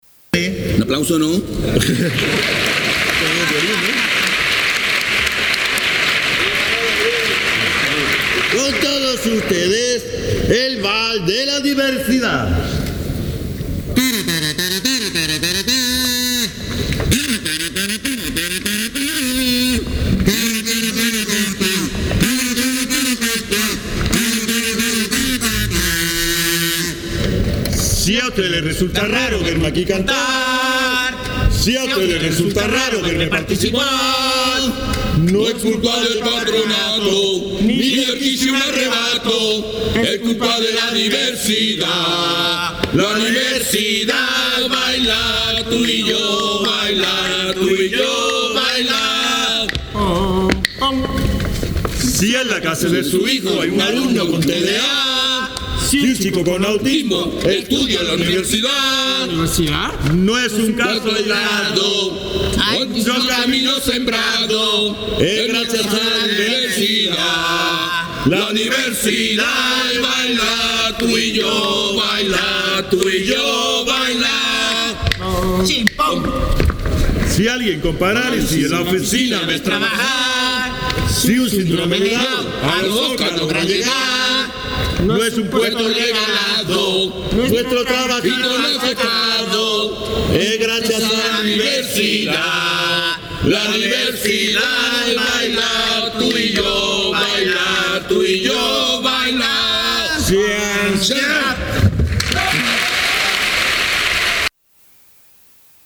El accésit este año en el que los Fermín Salvochea cumplen sus 28 años ha sido para el cuarteto de adultos ‘Merda, merda, mucha merda’, de El Puerto de Santa María, con la letra premiada